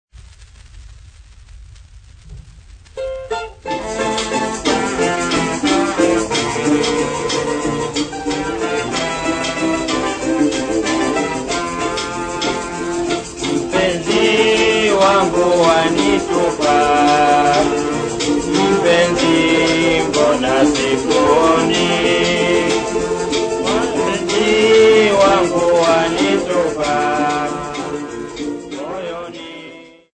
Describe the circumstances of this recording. Field recordings Africa Tanzania Dar-es-Salaam f-tz